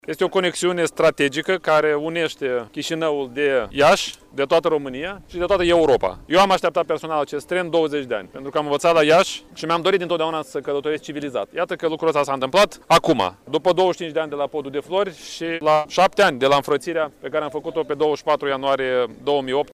Primarul Chişinăului Dorin Chirtoacă a precizat că trenul se va numi „Unirea”: